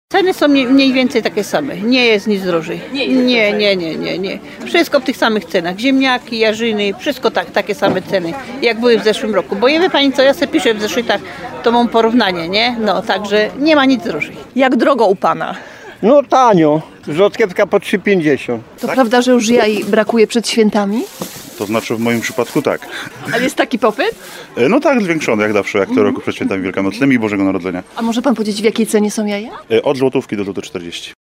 Mam porównanie – mówi nam prowadząca inny stragan.